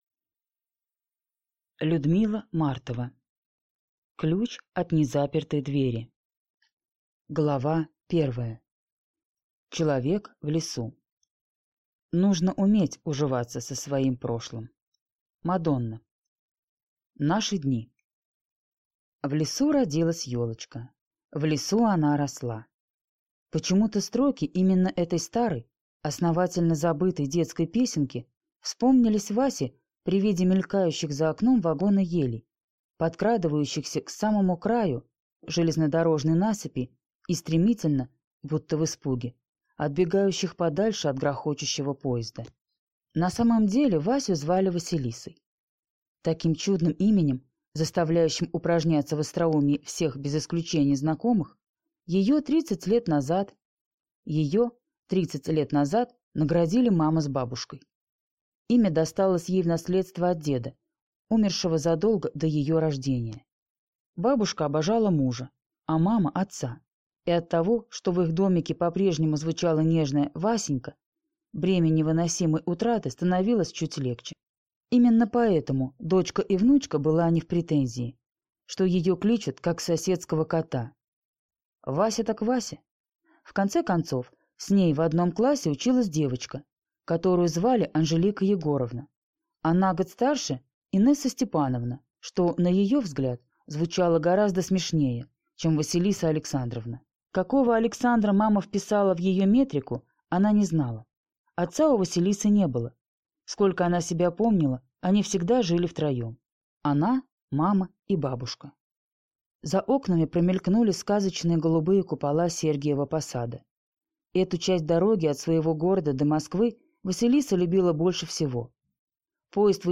Аудиокнига Ключ от незапертой двери | Библиотека аудиокниг
Прослушать и бесплатно скачать фрагмент аудиокниги